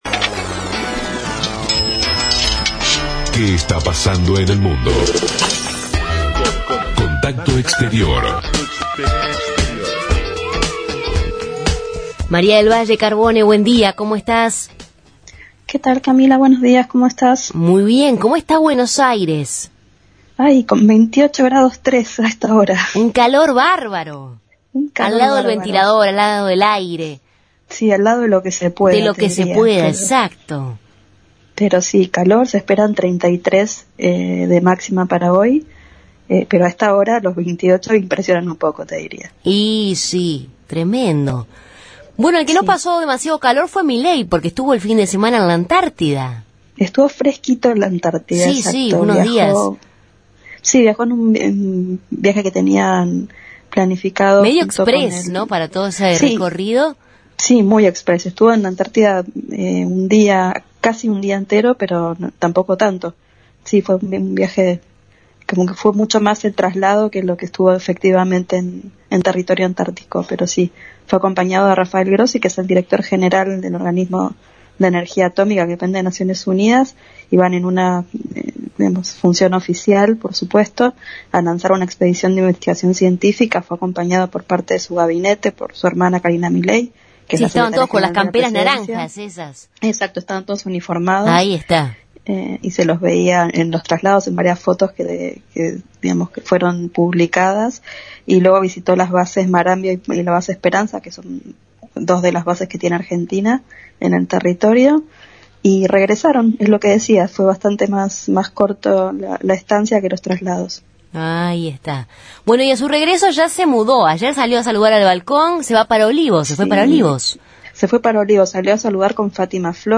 Corresponsal